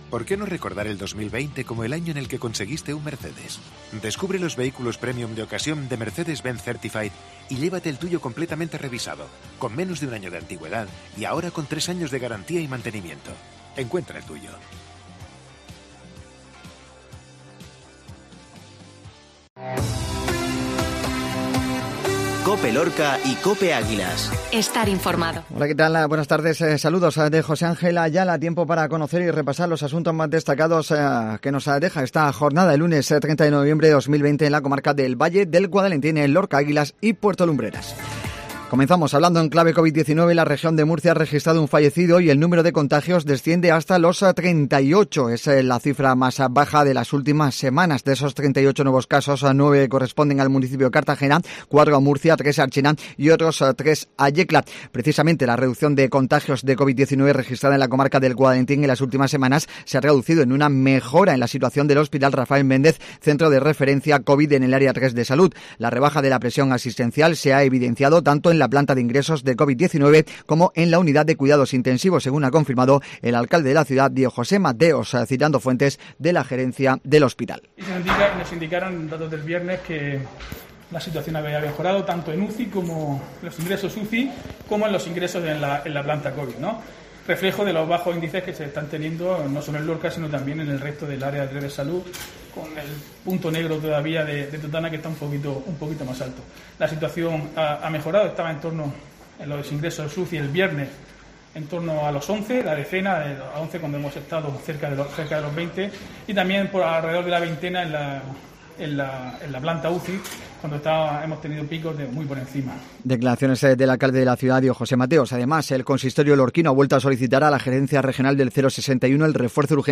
INFORMATIVO MEDIODÍA LUNES